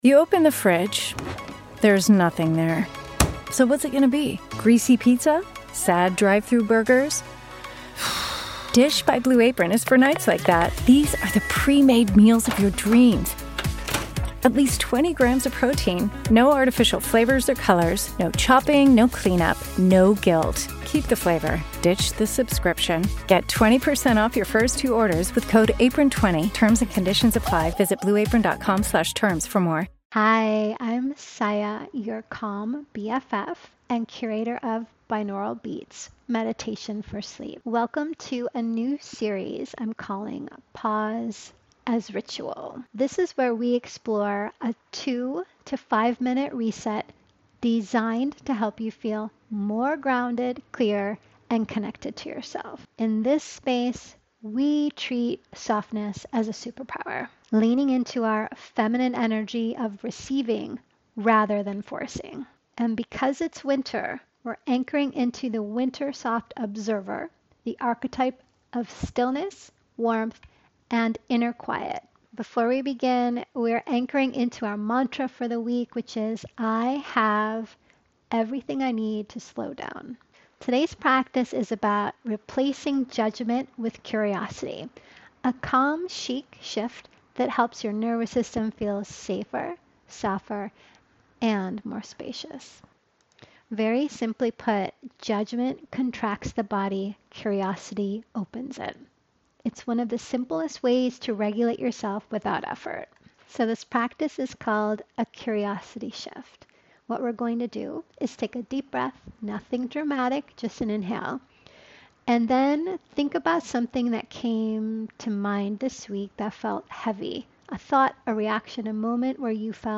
Binaural Beats Meditation for Sleep
This podcast is part of Ritual Era Meditation — a guided ritual experience